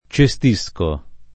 cestisco [ © e S t &S ko ]